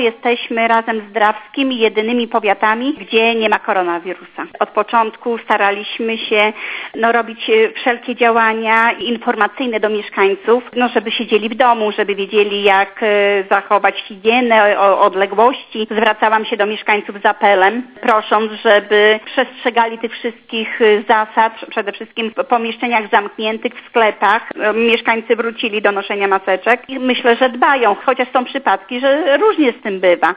O to, jak mieszkańcy powiatu gołdapskiego zdołali się uchronić przed COVID-19, zapytaliśmy starostę Marzannę Wardziejewską.